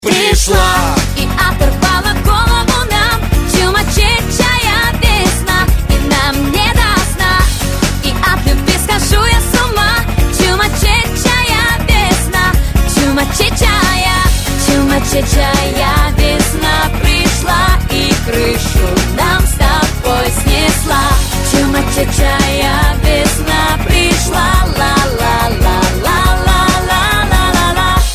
Главная » Файлы » Hip-Hop, RnB, Rap